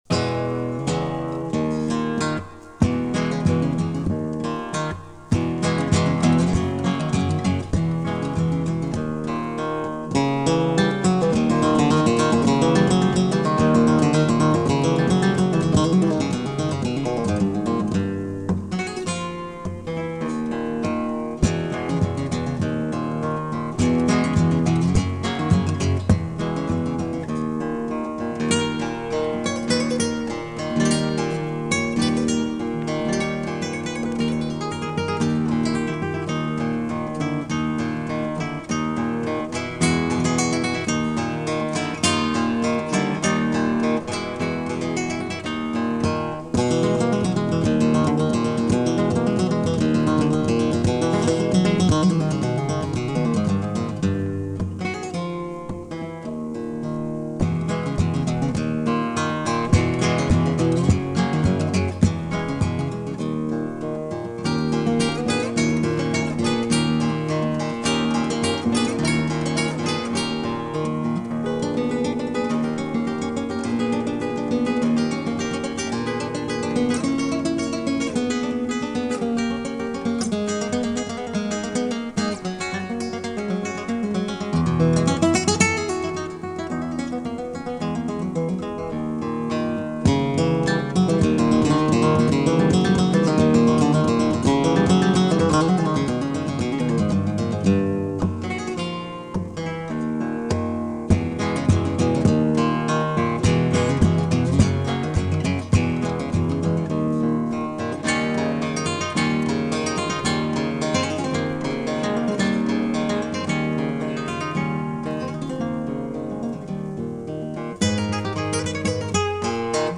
Flamenco Guitar